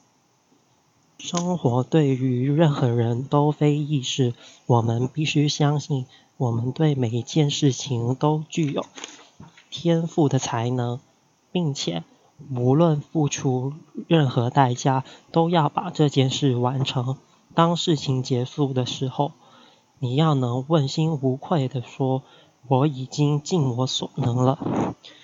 Task 3 Passage Reading
Gender : Male
First Language : Cantonese
Third Language : Mandarin